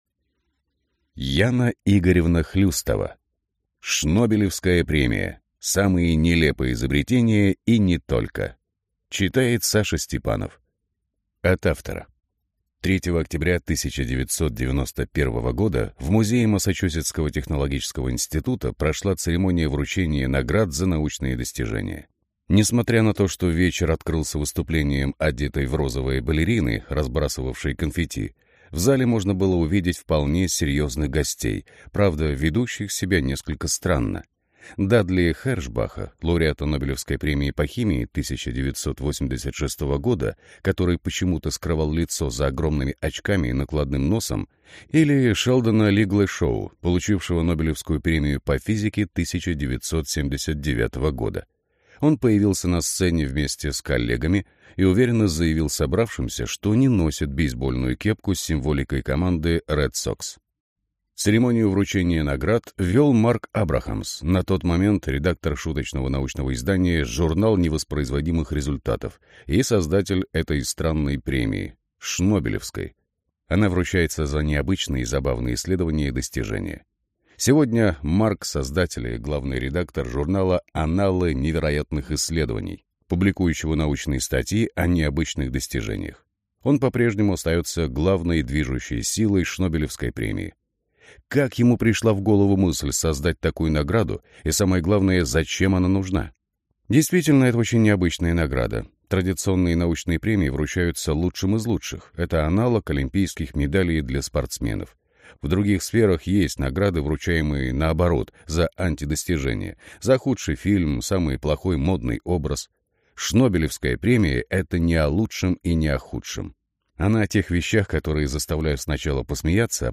Аудиокнига Шнобелевская премия. Самые нелепые изобретения и не только | Библиотека аудиокниг